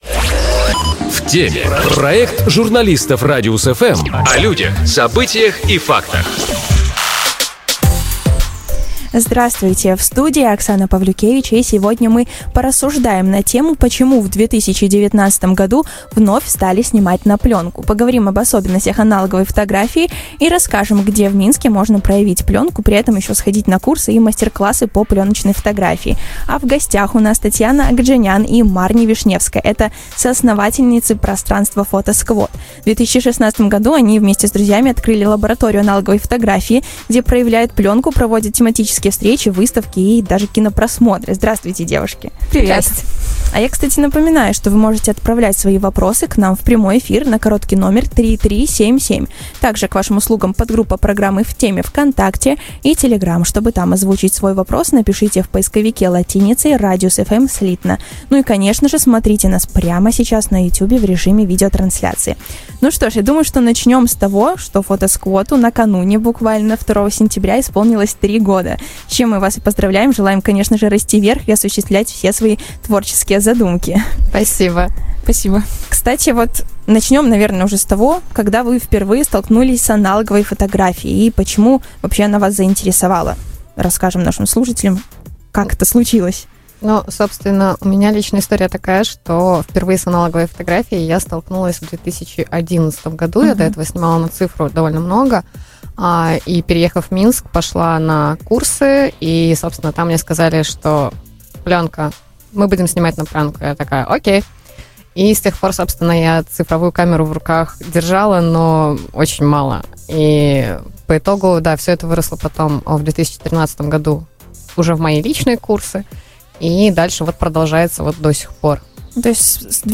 "В теме". Аналоговая фотография: зачем снимать на плёнку в 2019? | Шоу с толком | Радиус-FM